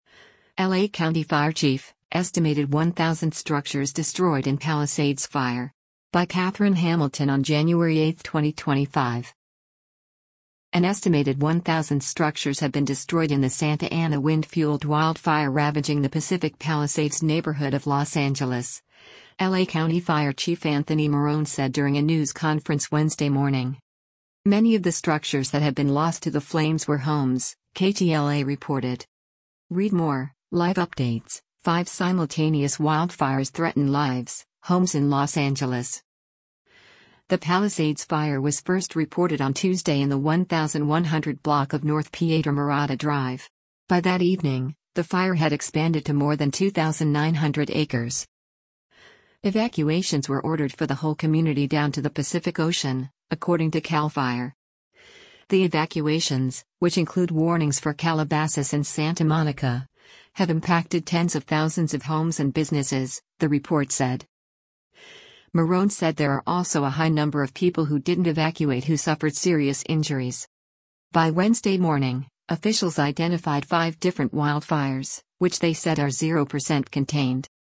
An estimated 1,000 structures have been destroyed in the Santa Ana wind-fueled wild fire ravaging the Pacific Palisades neighborhood of Los Angeles, L.A. County Fire Chief Anthony Marrone said during a news conference Wednesday morning.